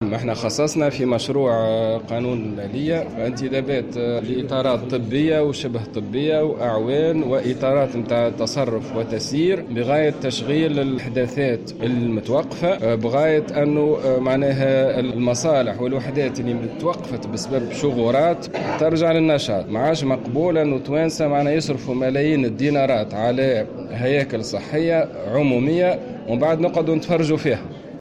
أكد اليوم وزير الصحة، عماد الحمامي، في تصريح لمراسلة "الجوهرة أف أم" برنامج انتدابات خصصته وزارة الصحة في مشروع قانون المالية 2019.
وقال إنه سيتم انتداب إطارات طبية وشبه طبية وأعوان وإطارات تصرّف وتسيير لفائدة منشآت صحّية معطّلة بسبب شغورات. وجاءت تصريحاته على هامش إشرافه اليوم على تركيز جهاز للكشف بالصدى لتقصّي سرطان الثدي بمستشفى محمد التلاتلي بولاية نابل.